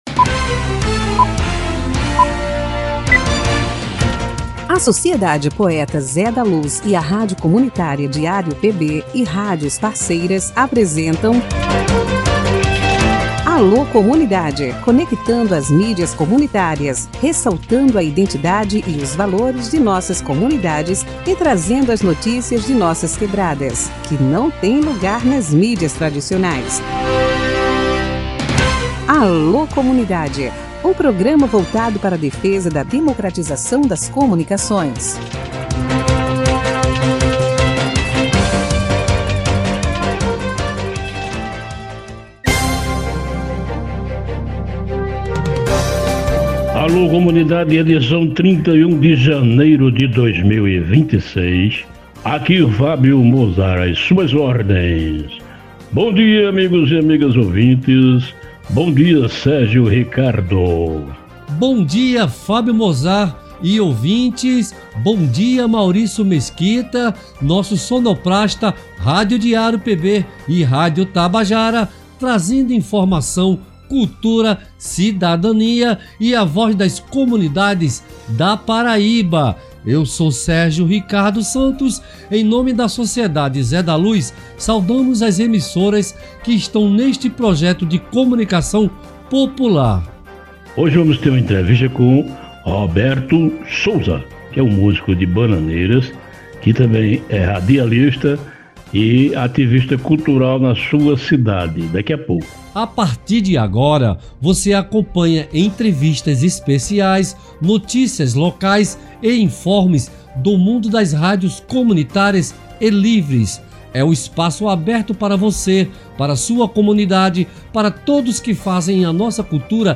Alô Comunidade, programa da Rádio DiarioPB e Sociedade Cultural Poeta Zé da Luz, parceria com a Rádio Tabajara da Paraíba, retransmitido no pós rádio por uma rede de rádios comunitárias e alternativas